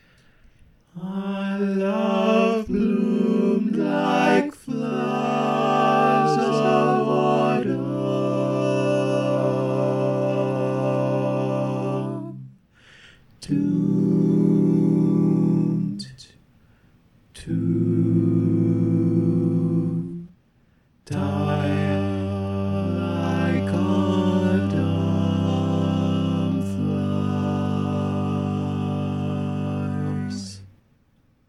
Key written in: G Major
How many parts: 4
Type: Barbershop
All Parts mix: